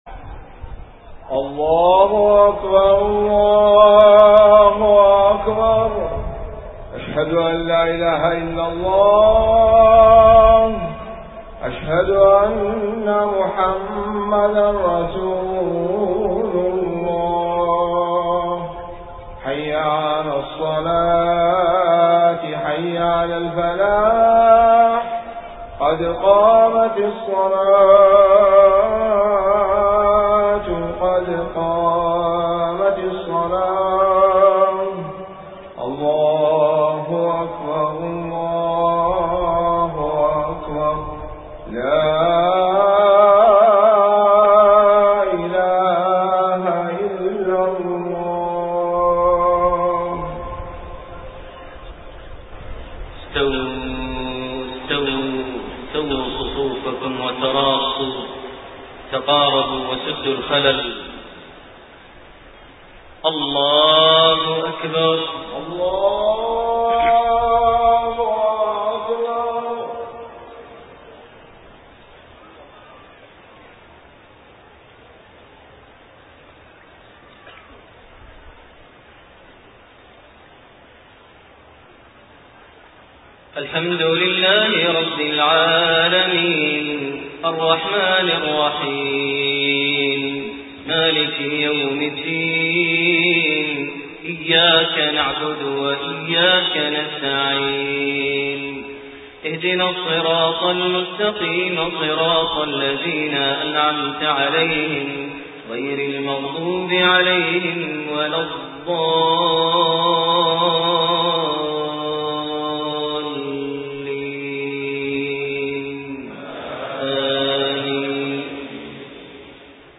صلاة العشاء 1-8-1428 خواتيم سورة الزمر67-75 > 1428 هـ > الفروض - تلاوات ماهر المعيقلي